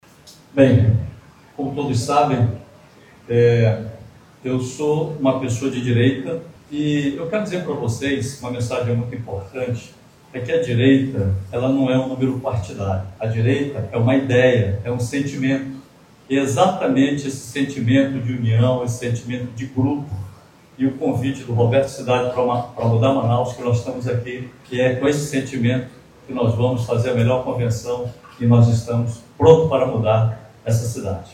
O anúncio foi feito no fim da tarde desta terça-feira (30), em coletiva de imprensa com a participação dos dois políticos e outros aliados, no Conjunto Vieiralves, zona centro sul da capital.